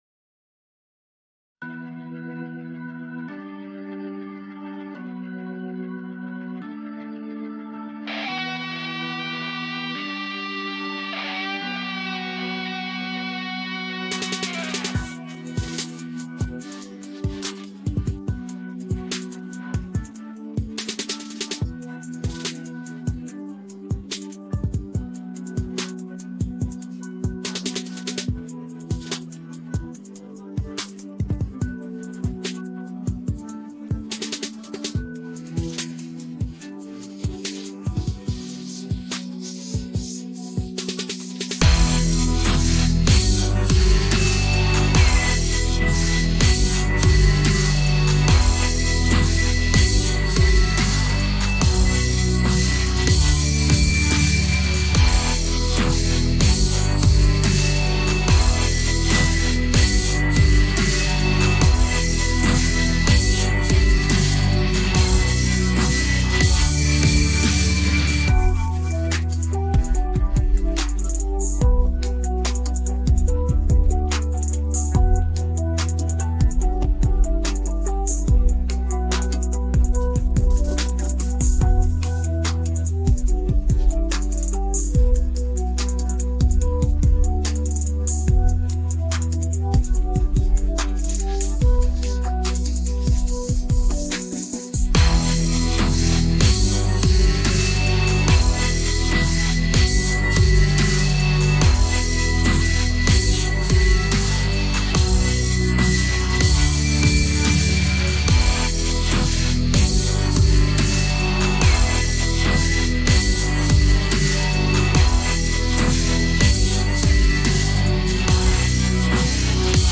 мінус караоке